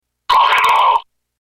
Chaque bouchon a une voix enregistrée qui lui est associée, cliquez sur le nom du bouchon pour l'écouter.